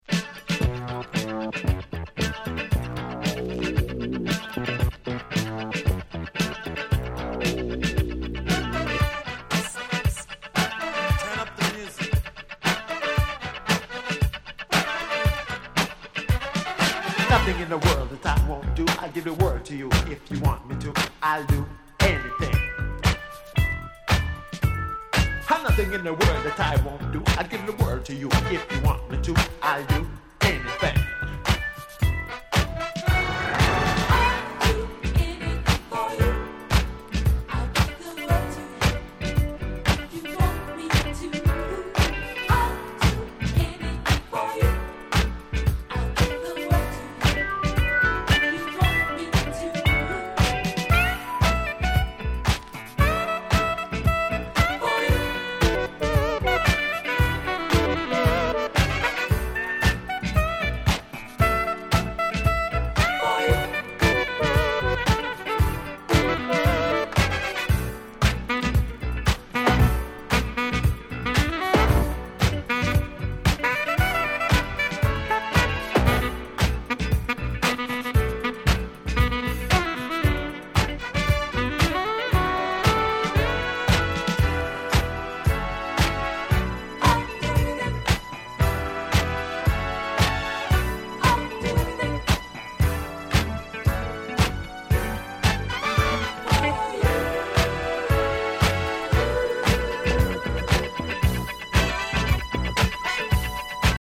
81' Big Hit Dance Classics !!